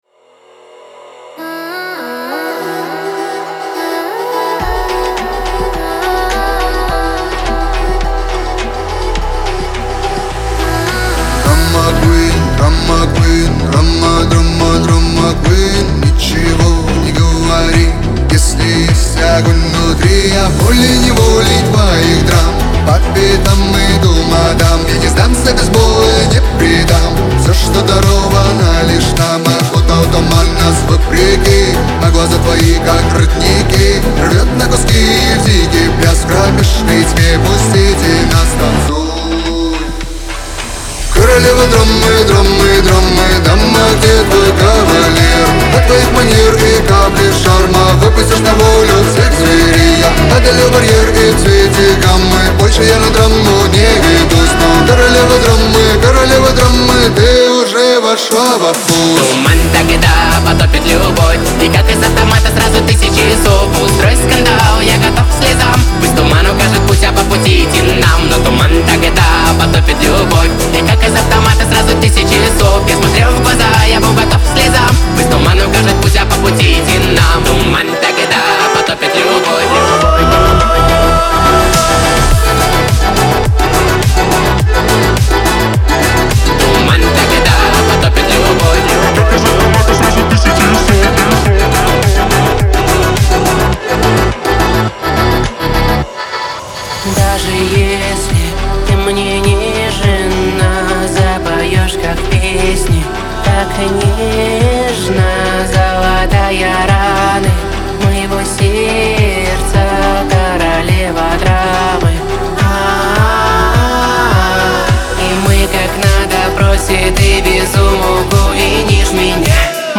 диско , эстрада , дуэт
pop